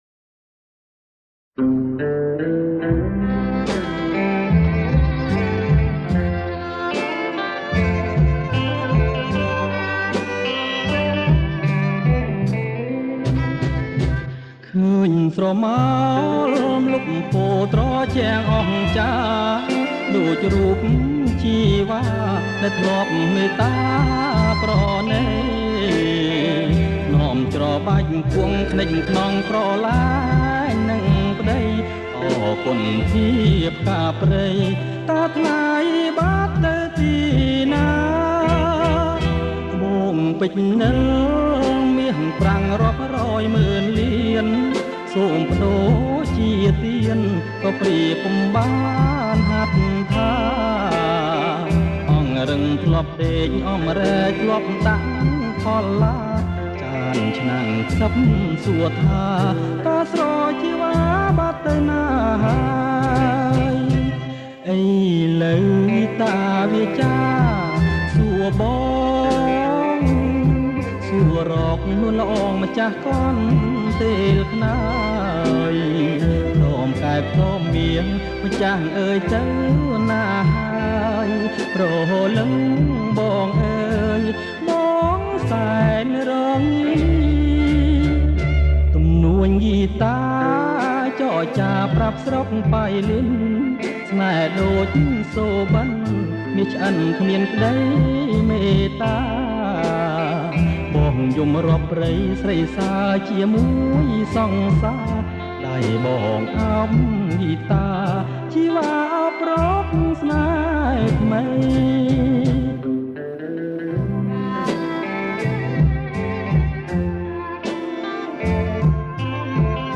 • ប្រគំជាចង្វាក់ Bolero Begun